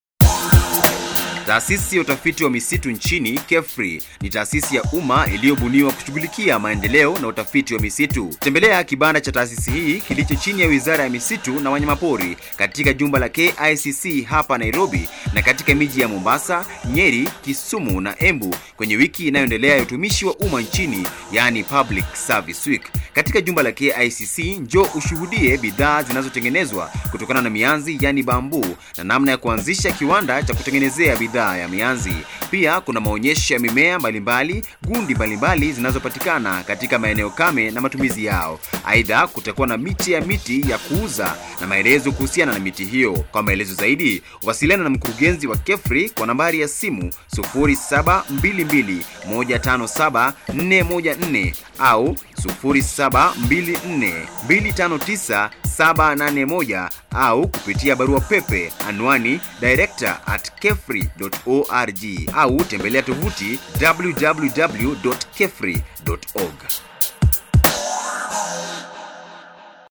public service week radio announcement.mp3
summary KBC radio announcement of KEFRI's participation at the public service week held at KICC grounds on 13th - 15th October 2009.